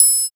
113 TRIANGLE.wav